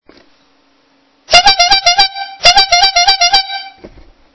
We wholesale a wide selection of noisy horns.
903001 Honk Honk Horn - blue
metal_horn.MP3